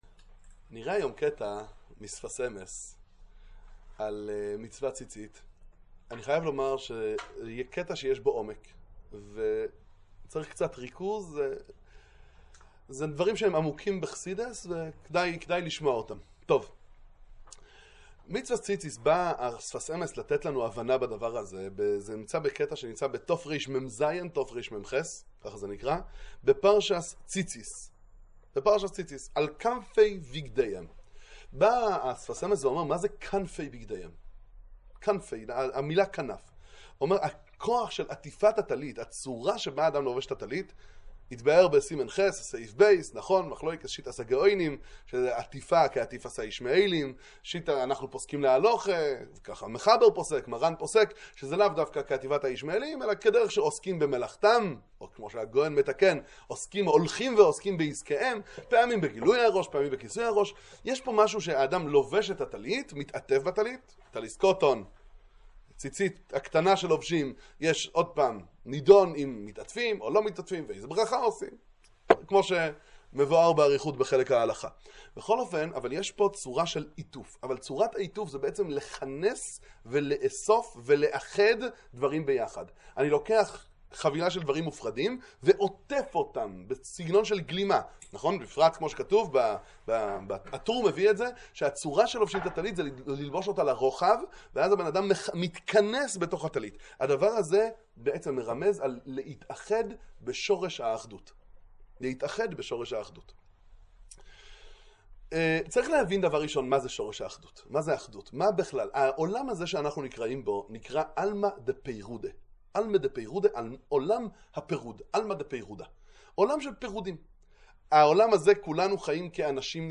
שיעור בעומק דברי השפת אמת, להבנת מצוות ציצית